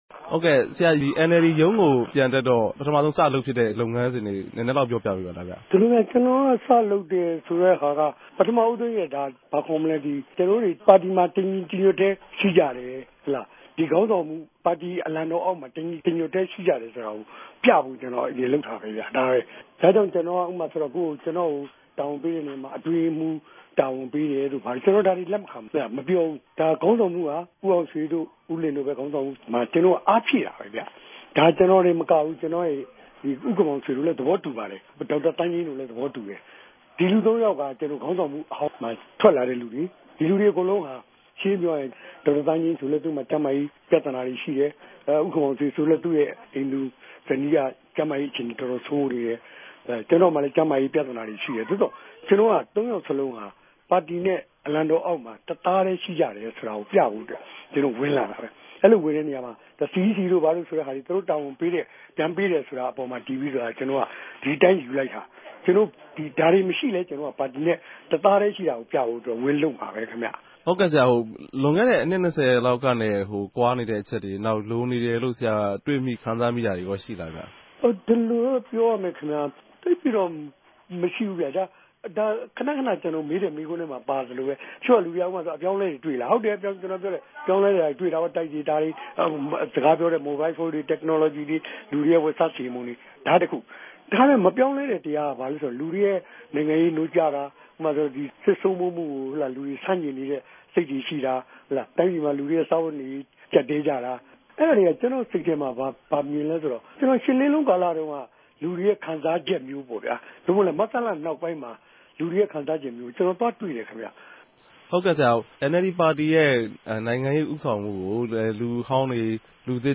ဦးဝင်းတငိံြင့် ဆက်သြယ်မေးူမန်းခဵက်။